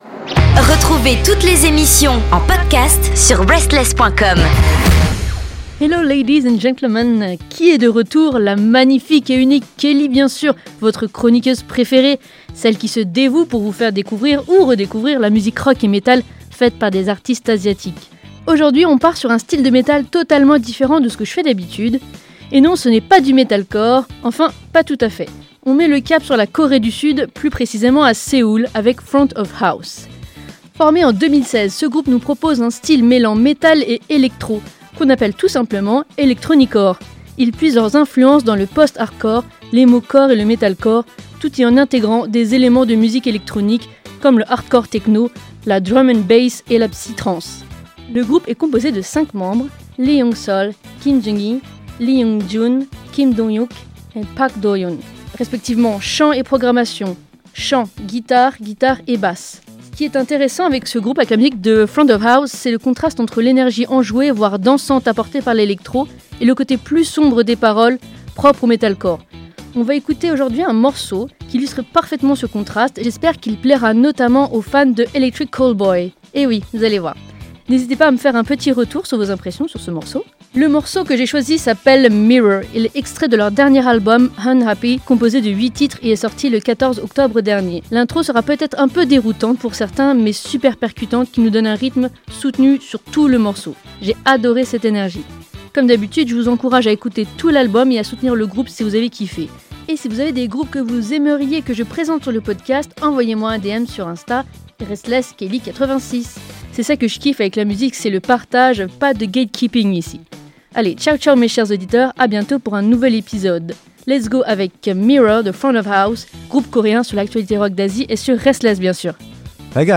Un groupe unique en son genre sur la scène métal coréenne. FRONT OF HOUSE propose un mélange entre musique électronique et metalcore qui rassemblera , je l’espère, les fans de ces deux genres musicaux.